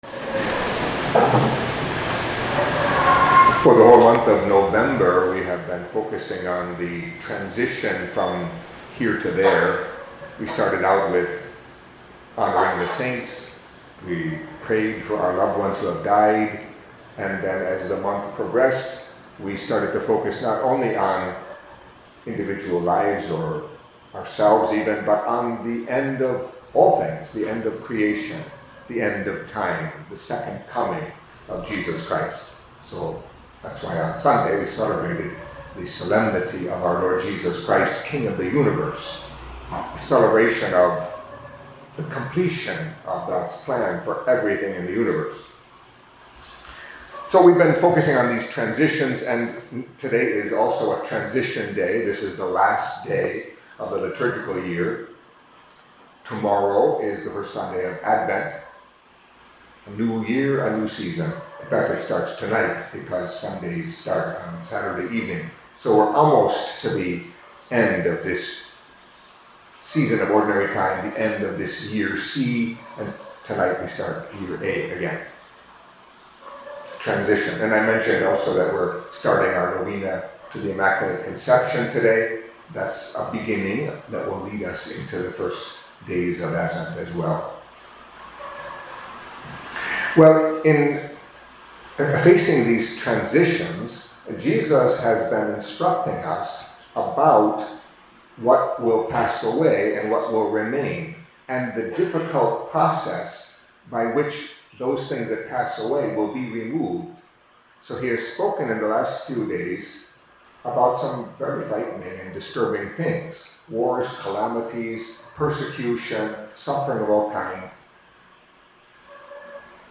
Catholic Mass homily for Saturday of the Thirty-Fourth Week in Ordinary Time